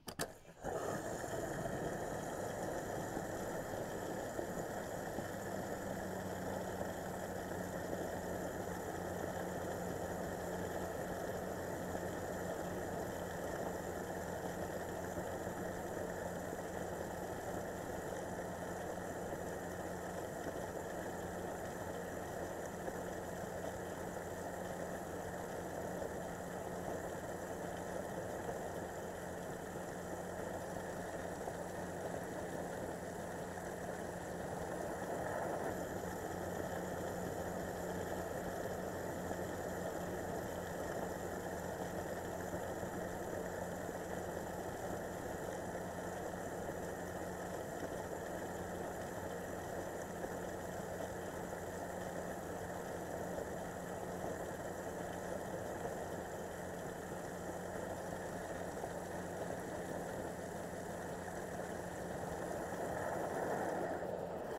refuel.ogg